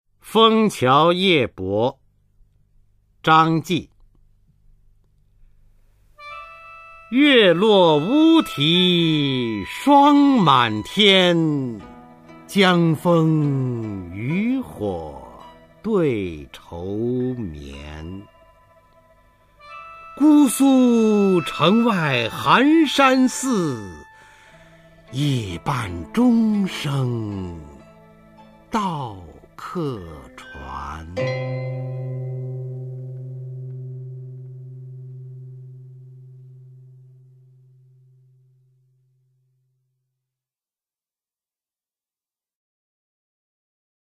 [隋唐诗词诵读]张继-枫桥夜泊（男） 配乐诗朗诵